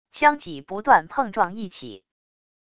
枪戟不断碰撞一起wav音频